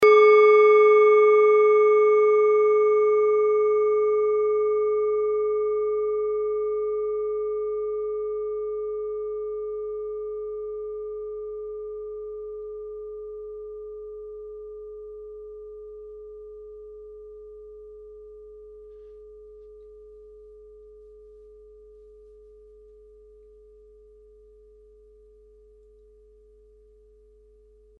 Kleine Klangschale Nr.2
Klangschale-Gewicht: 340g
Klangschale-Durchmesser: 11,7cm
Diese Klangschale ist eine Handarbeit aus Bengalen. Sie ist neu und ist gezielt nach altem 7-Metalle-Rezept in Handarbeit gezogen und gehämmert worden.
(Ermittelt mit dem Minifilzklöppel)
kleine-klangschale-2.mp3